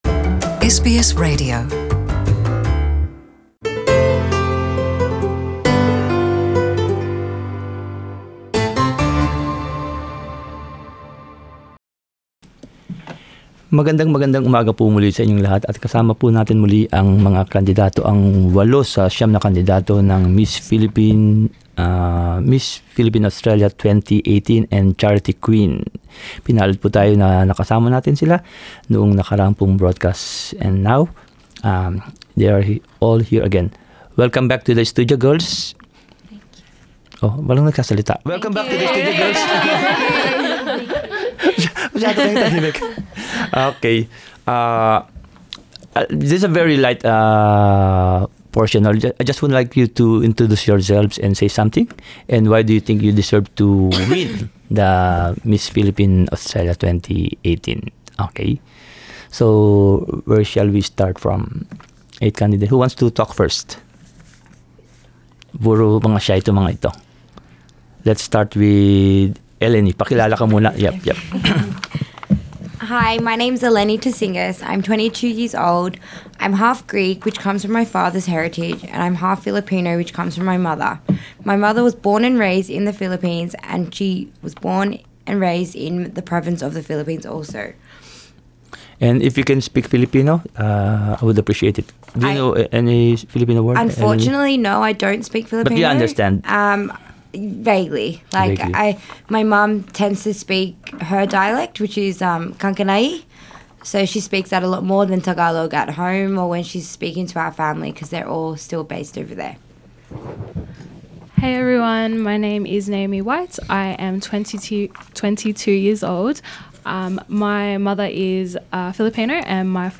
Before the competition heats up as the grand coronation night draws nearer, SBS Filipino sat with eight of the nine finalists in a fun, light and ideal-filled conversation.